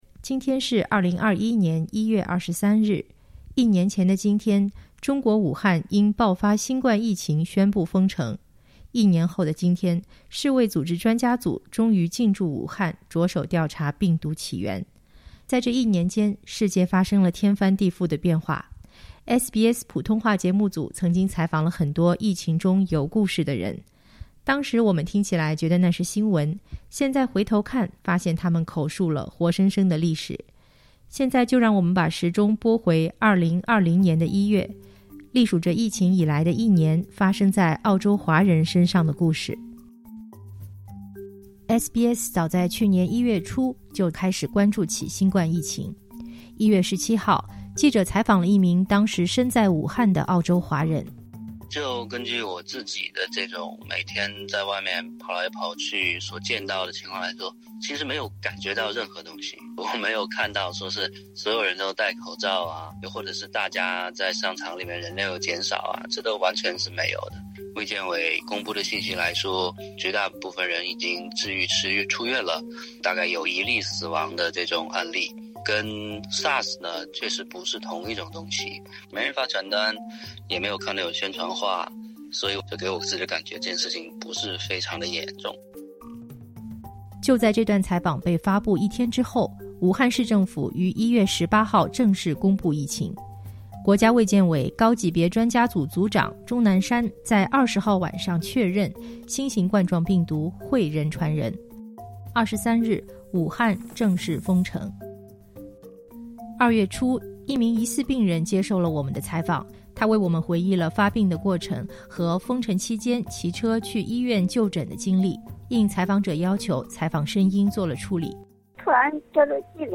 应采访者的要求，采访声音做了处理。